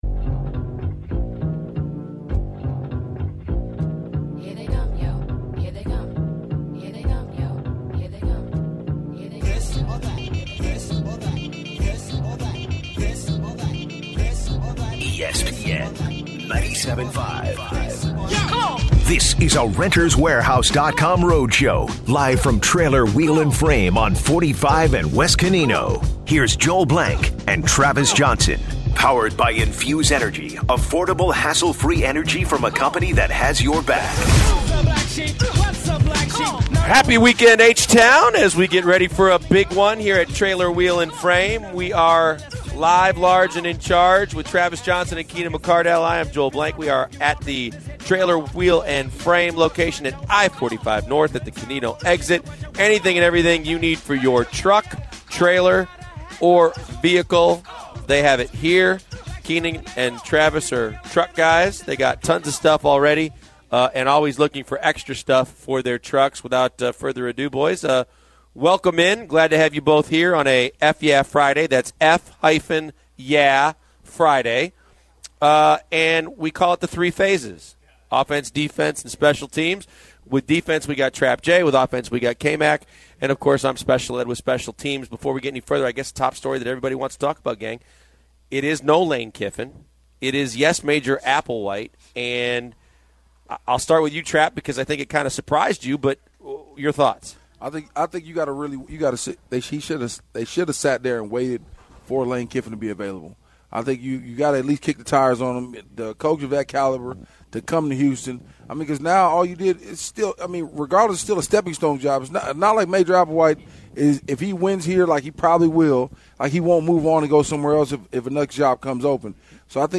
They begin the hour with Major Applewhite becoming the next head coach of the U of H cougars. Celtics legend Kevin McHale calls in to talk Houston Rockets and the D-Mo debacle and how well the rockets have been playing defensively and the upcoming slate of games.